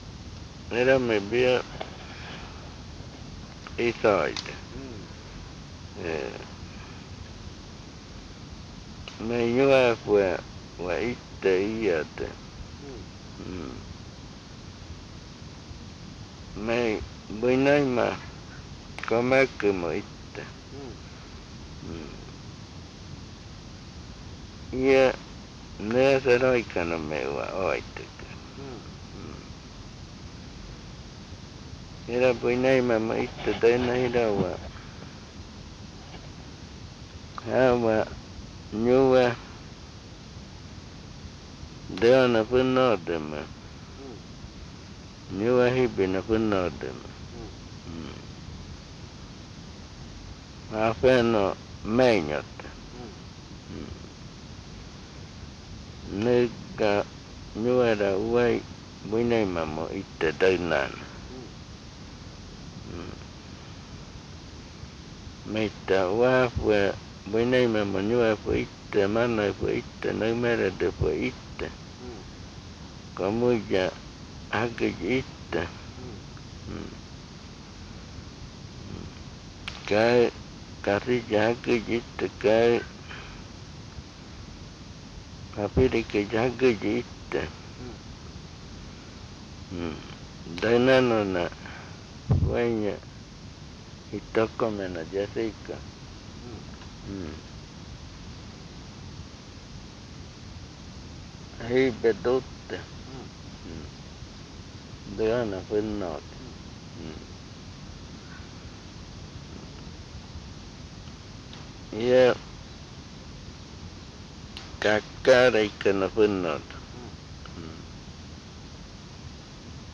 Esta palabra fue grabada hacia el final de un año de trabajo de grabaciones.